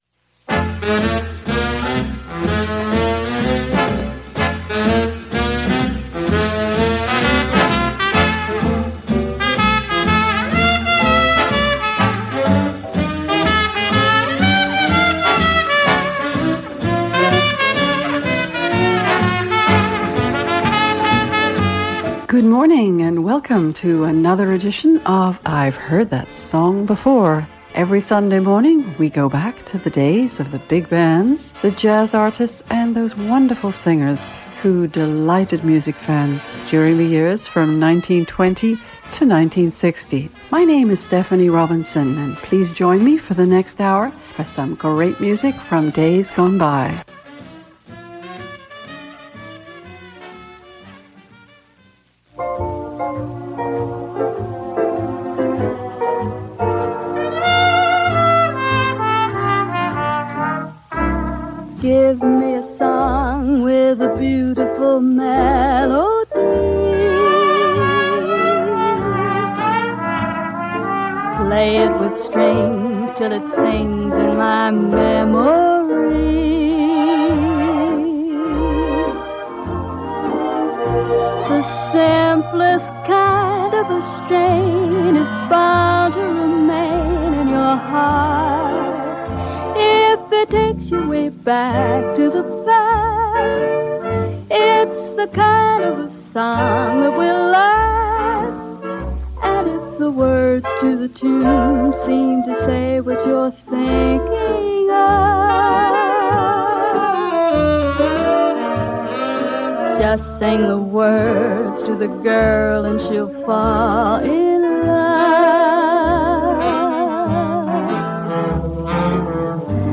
She is remembered particularly for the warmth of her voice, for her interpretative skills and for her talent in matching lyrics to the music of the big bands during the Swing Era and beyond.